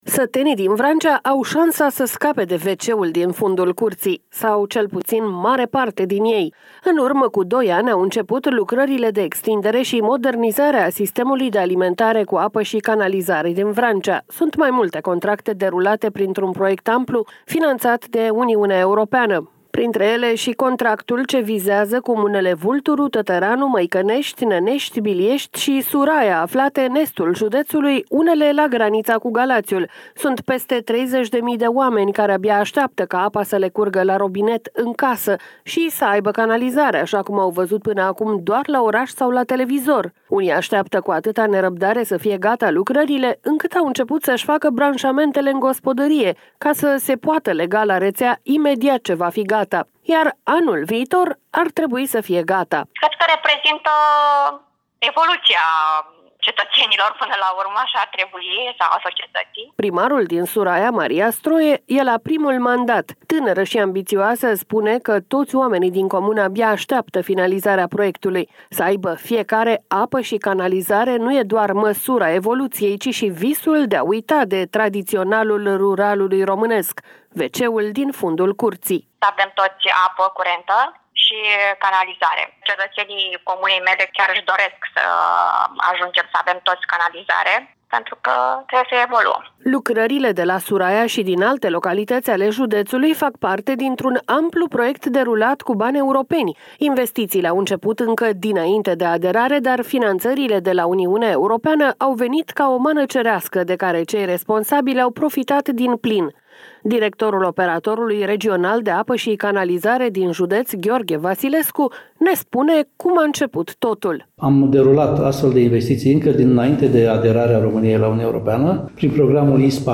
Lumea Europa FM: Apă și canal în Vrancea | Fonduri europene | REPORTAJ